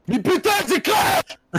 Play, download and share RAGE CRASH original sound button!!!!
rage-crash.mp3